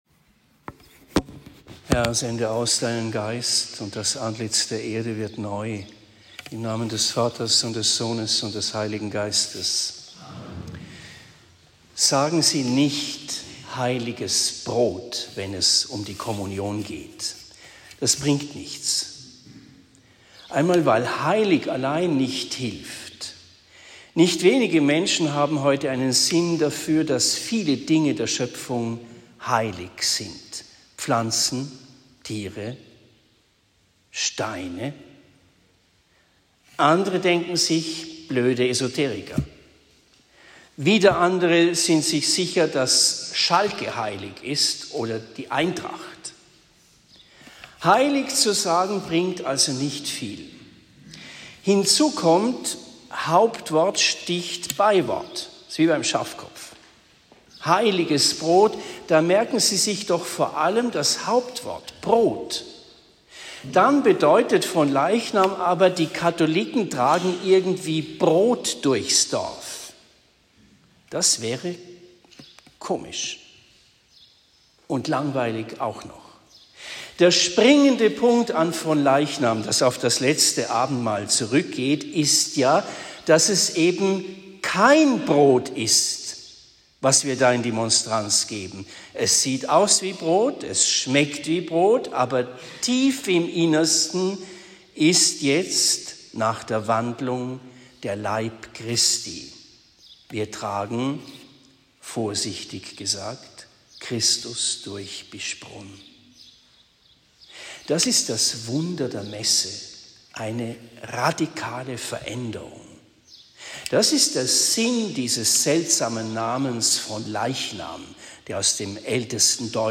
Predigt in Tiefenthal am 11. Juni 2023